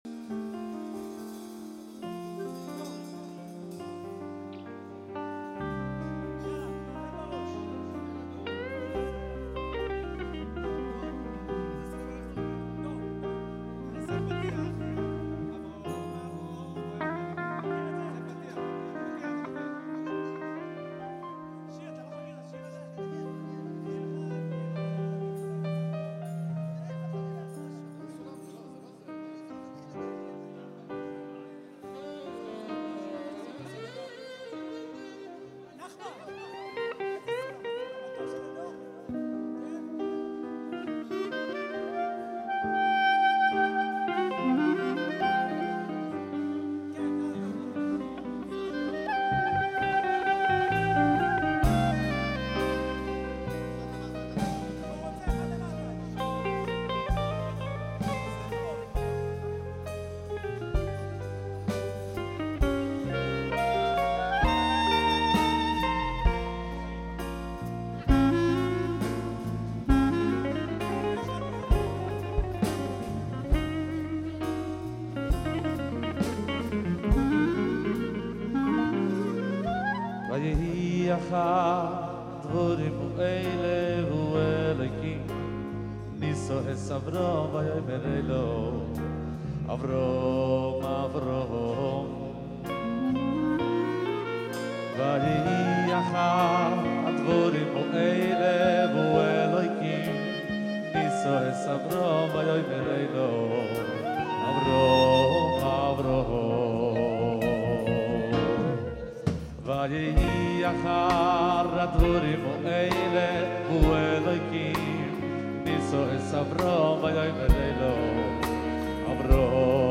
ניגוני כלייזמר מוכרים